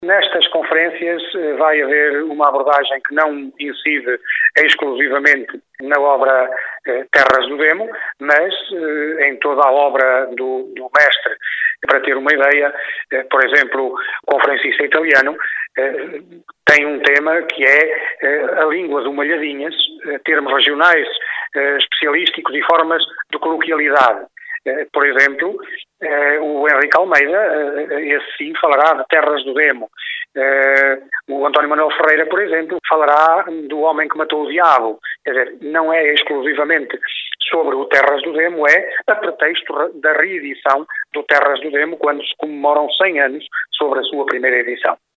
A primeira Jornada Internacional Aquiliniana vai abordar as várias obras deixadas pelo escritor, refere o autarca José Eduardo Ferreira